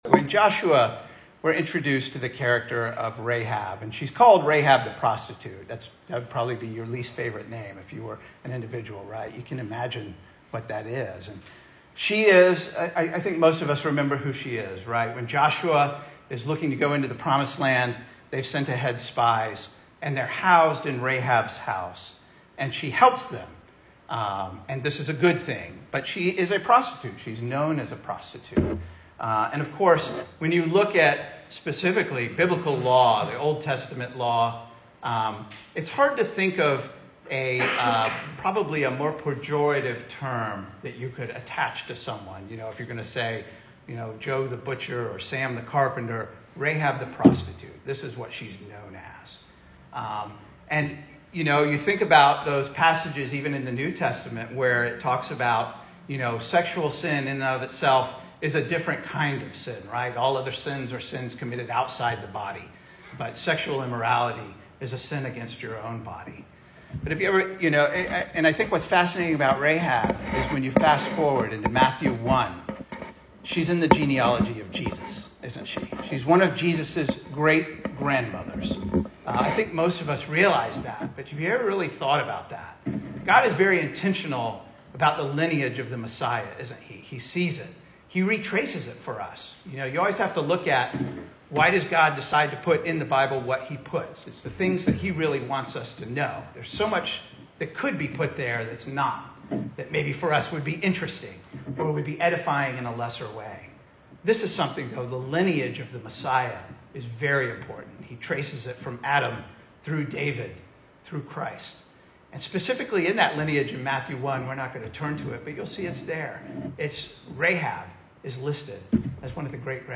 Service Type: Sunday Evening
Series: Single Sermons